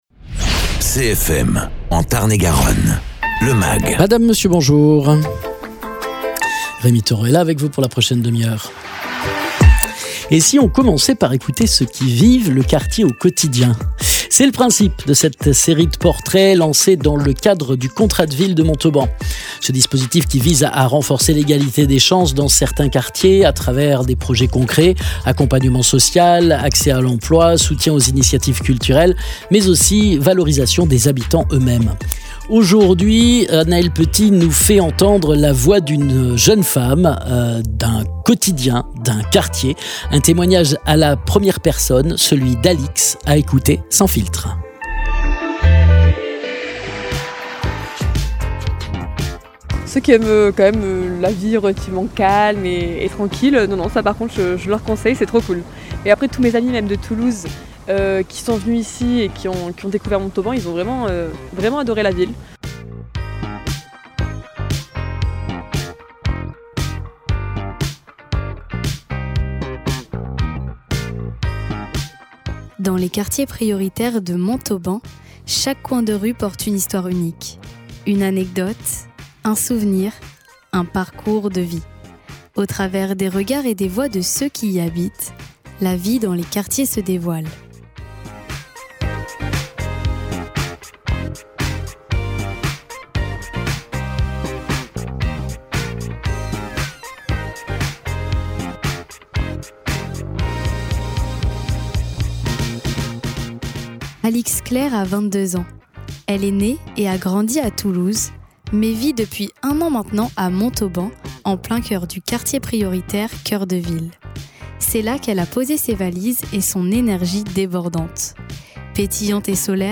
Portrait
Nous nous installons proche de la fontaine, en face du jardin des plantes.